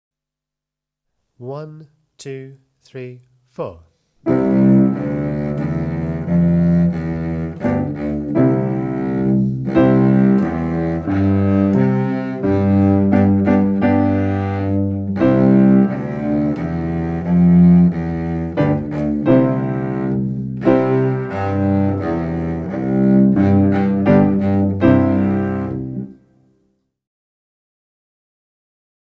56 Ring my number (cello)